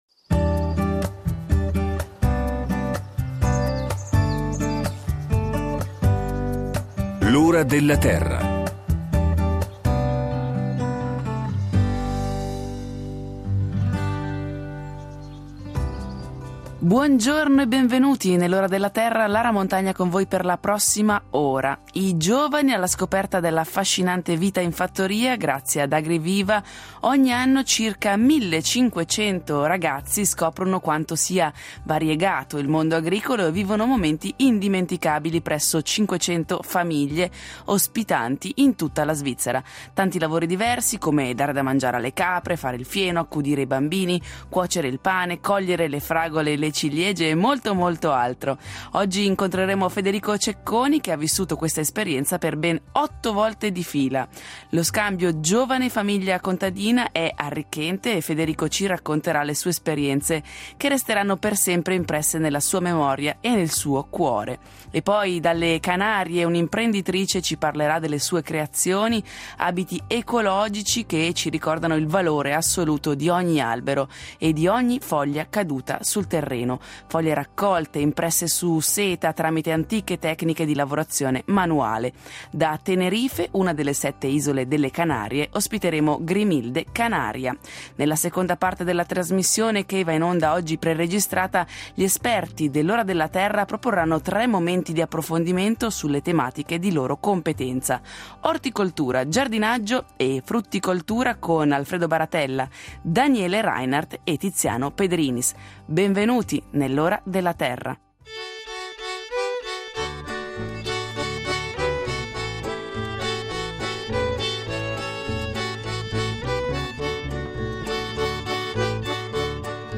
Nella seconda parte della trasmissione che va in onda pre-registrata